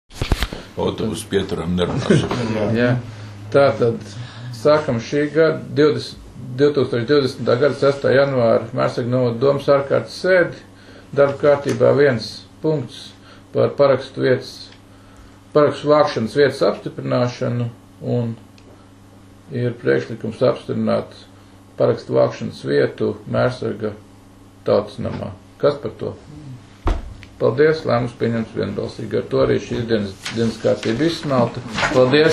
Mērsraga novada domes sēde 06.01.2020.